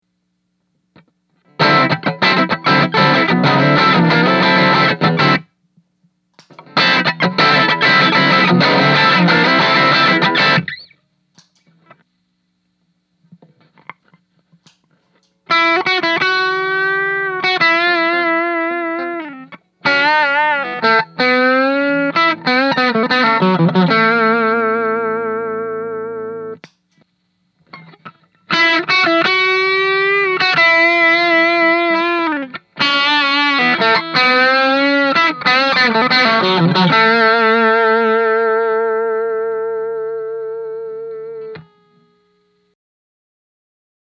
In both clips, I have my Aracom VRX22 set up in its drive channel with the master and and volume knobs set to about 1pm each.
With a Strat, these gain positions set it at the edge of breakup, and I have to really dig in to get distortion out of the amp.
The first clip is just a raw recording switching between just the Strat cranked up in its bridge position, then playing the same thing with the Timmy engaged. I did add a bit of extra volume to the Timmy so I could make sure that the amp got pushed into its sweet spot.